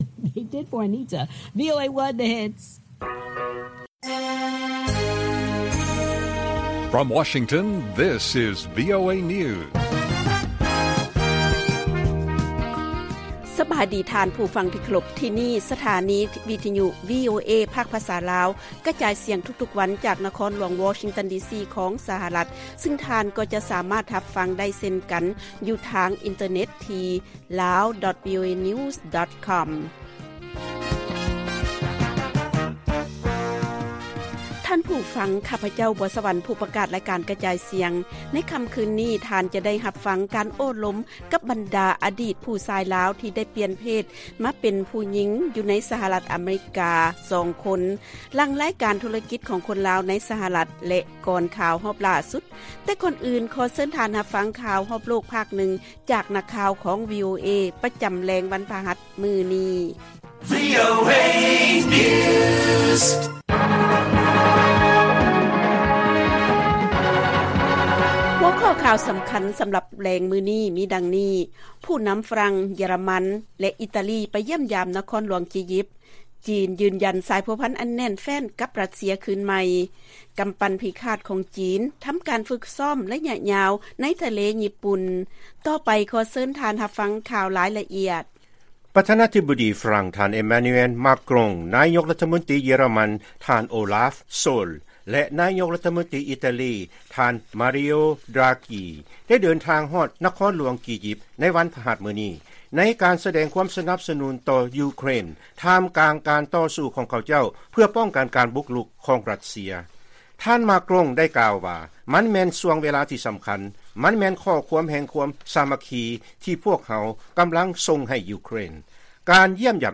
ລາຍການກະຈາຍສຽງຂອງວີໂອເອ ລາວ: ຜູ້ນຳ ຝຣັ່ງ, ເຢຍຣະມັນ ແລະ ອິຕາລີ ຢ້ຽມຢາມນະຄອນຫຼວງ ກີຢິບ